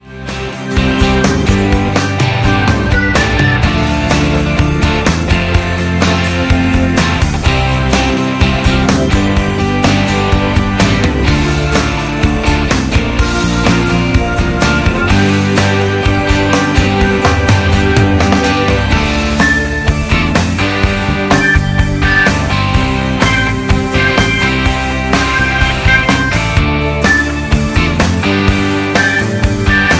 Backing track Karaoke
Pop, Duets, 1990s